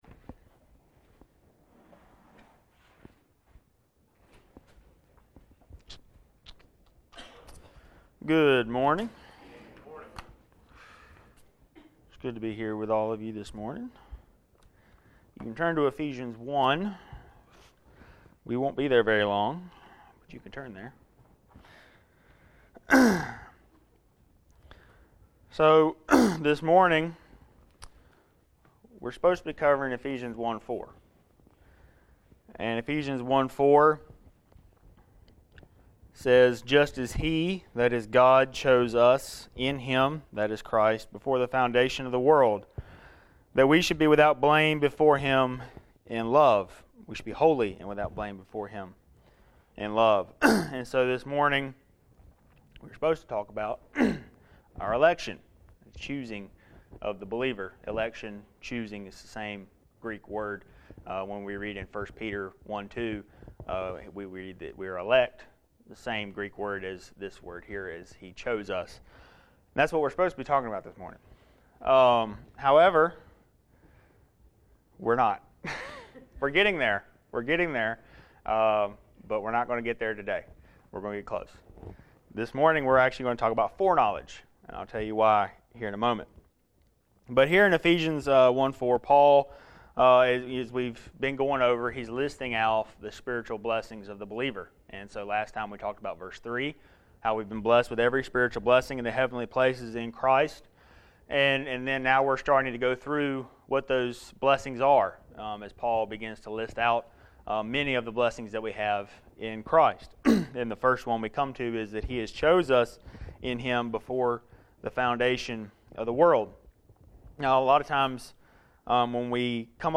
1 Peter 1:2 Service Type: Sunday Morning Related « Popularity on the Broadway My God